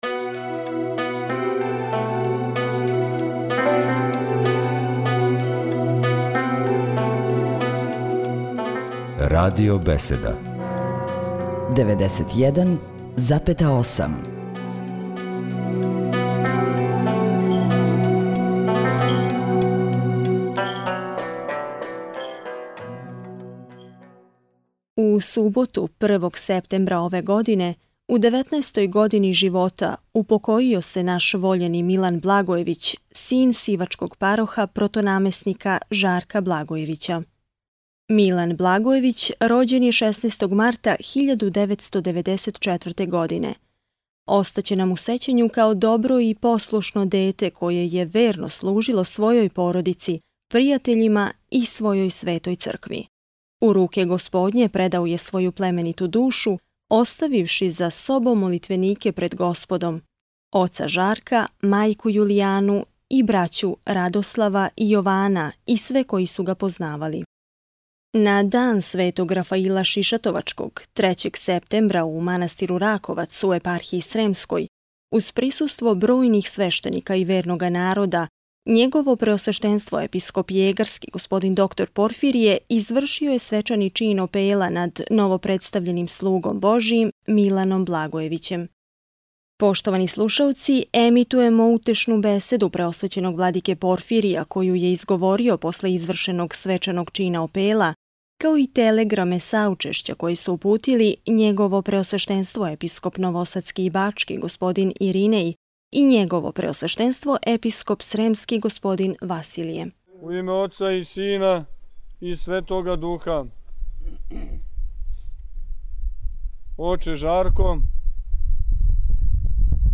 Беседа Преосвећеног владике Порфирија
Раковац